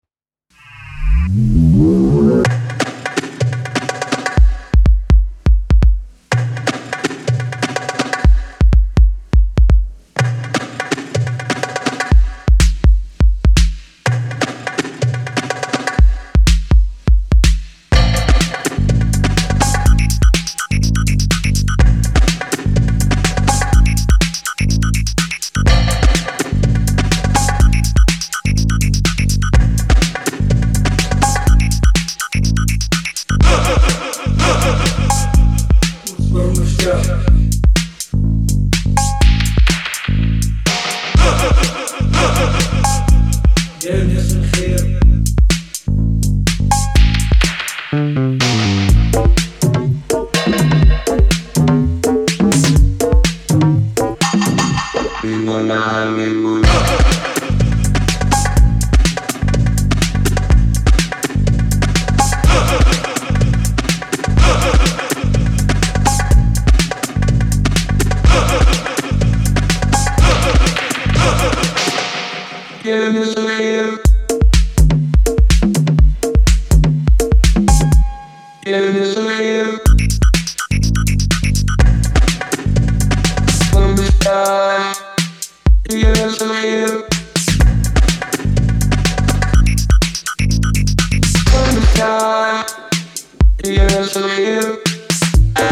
ニューウェイヴにも通じるサイケデリックなエレクトロ・ファンク